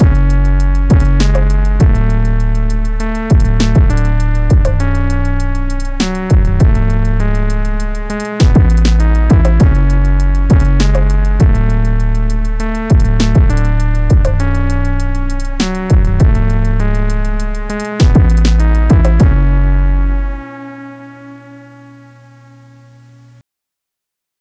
Just used a lil trappy beat I had on my M:S, and made sure to carefully align everything so it was in phase.
I didn’t get the hardware plugin part set up so everything is recorded through my audio interface into Reaper from the pedal’s stereo output.
There might be a bit of 60hz hum in the distorted versions, but I haven’t taken the time to see if that’s dirty power or not, so it could just be how i’ve set everything up for tonight.
Light Distortion: